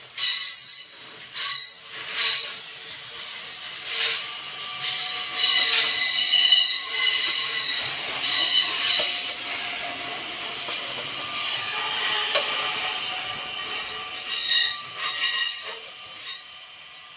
So klingt es direkt beim Pflegezentrum und dem betreuten Wohnen,
Zug_quietschen.mp3